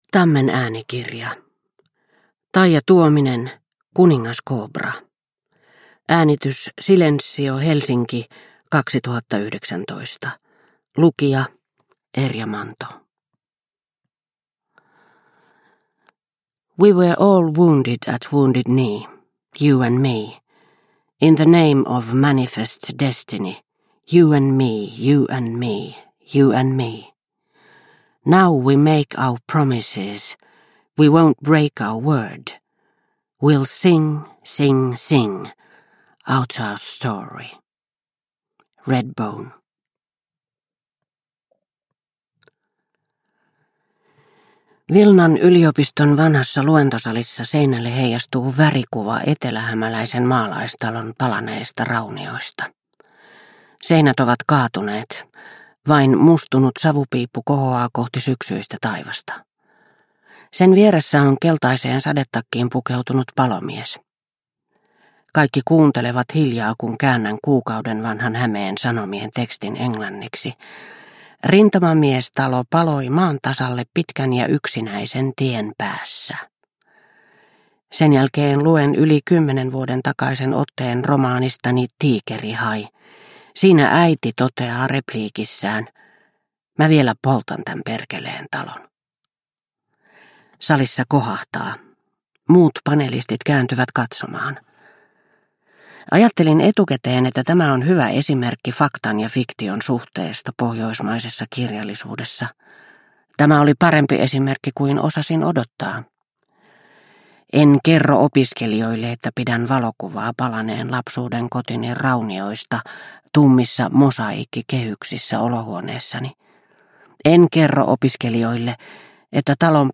Kuningaskobra – Ljudbok – Laddas ner